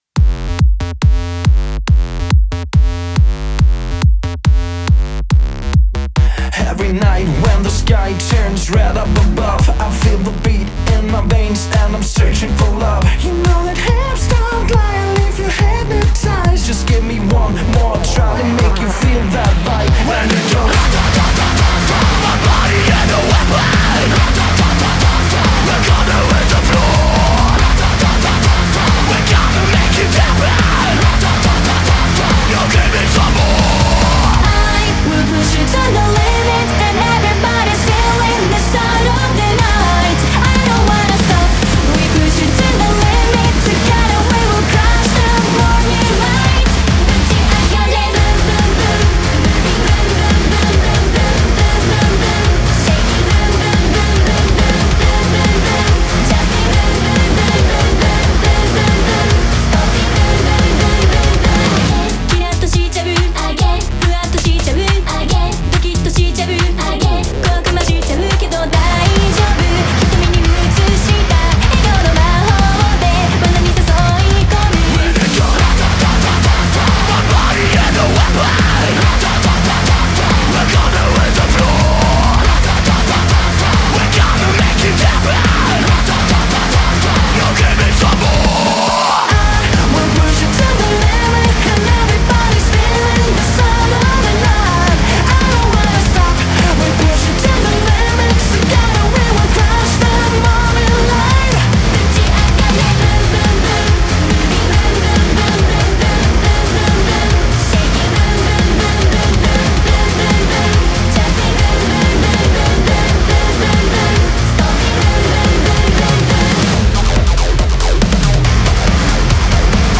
BPM140
Audio QualityPerfect (High Quality)
Your song must contain both male and female vocals.